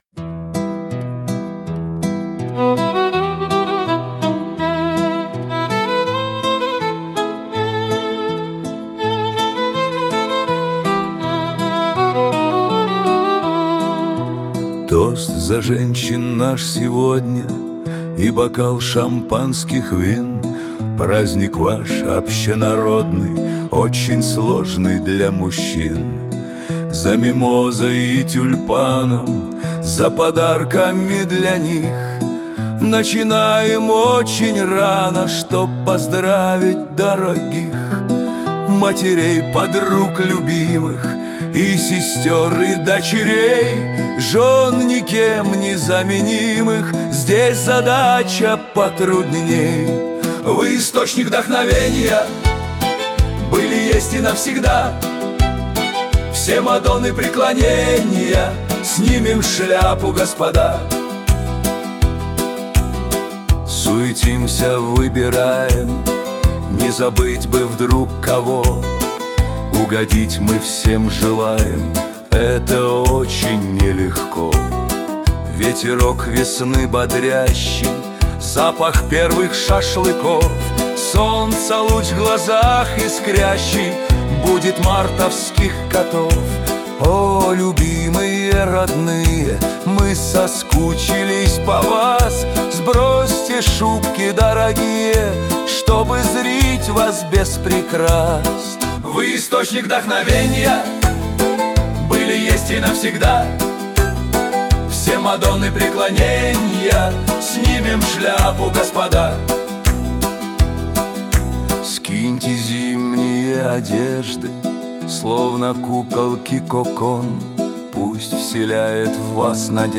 Песня-Поздравление